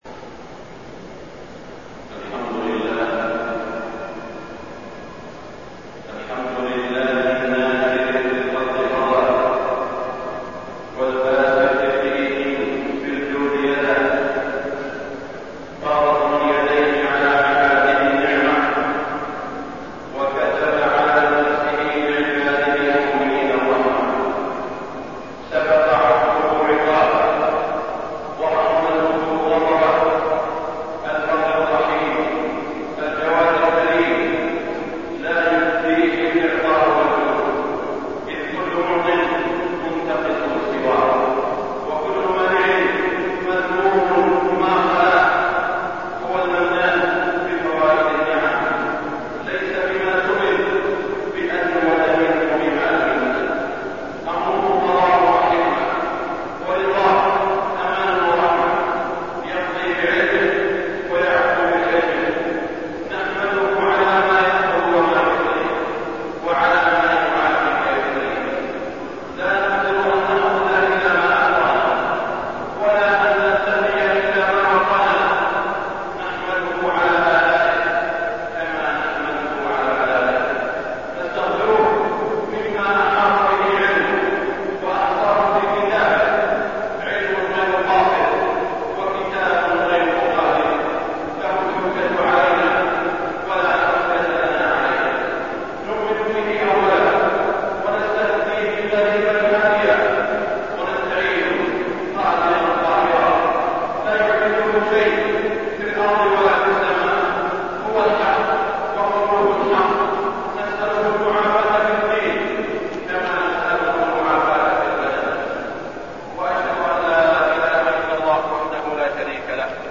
خطبة الاستسقاء 2-9-1419هـ > خطب الاستسقاء 🕋 > المزيد - تلاوات الحرمين